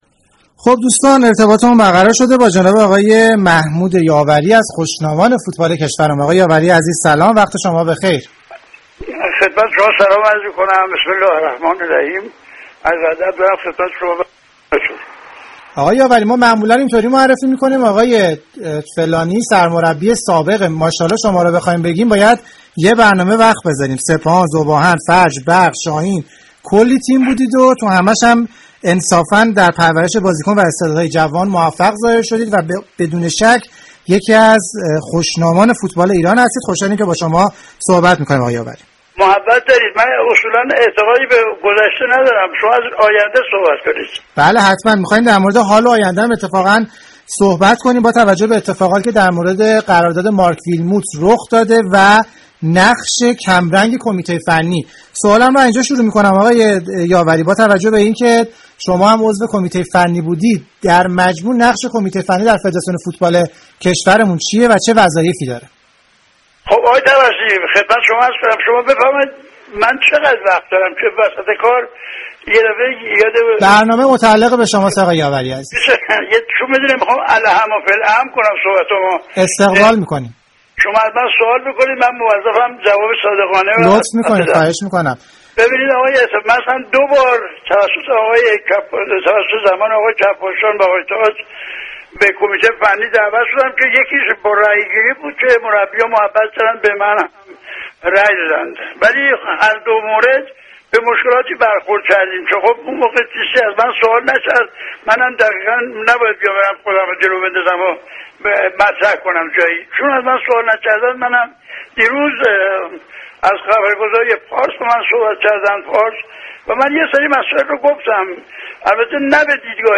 برنامه زنده